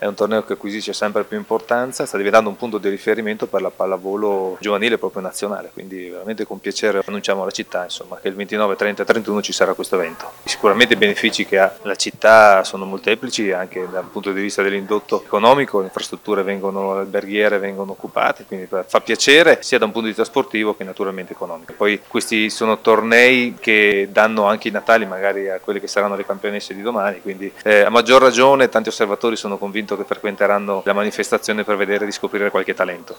Al nostro microfono Filippo Rando, assessore allo sport del Comune di Verona:
Filippo-Rando-assessore-allo-sport-del-comune-di-Verona.mp3